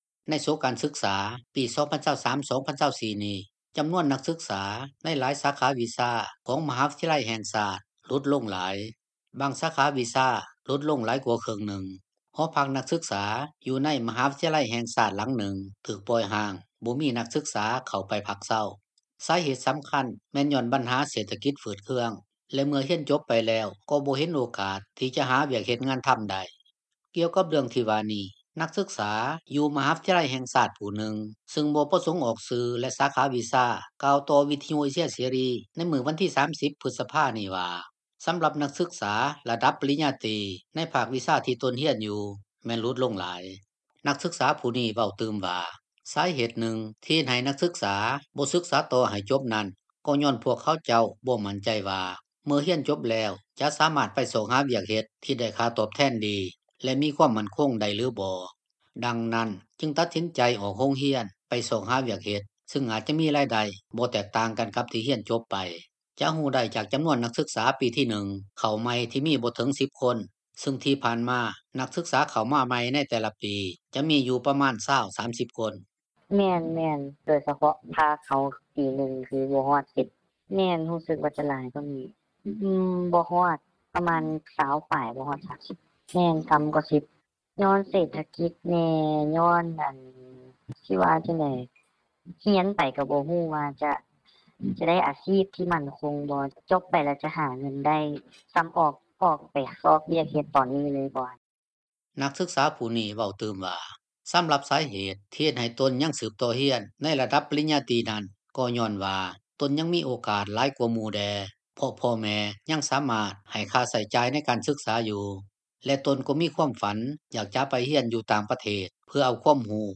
ກ່ຽວກັບເລື່ອງທີ່ວ່ານີ້, ນັກສຶກສາ ຢູ່ມະຫາວິທະຍາໄລ ແຫ່ງຊາດ ຜູ້ໜຶ່ງ ຊຶ່ງບໍ່ປະສົງອອກຊື່ ແລະສາຂາວິຊາຮຽນ ກ່າວຕໍ່ວິທຍຸເອເຊັຽເສຣີ ໃນມື້ວັນທີ 30 ພຶດສະພານີ້ວ່າ ສຳລັບນັກສຶກສາ ລະດັບປະລິນຍາຕີ ໃນພາກວິຊາທີ່ຕົນຮຽນຢູ່ ແມ່ນຫຼຸດລົງຫຼາຍ.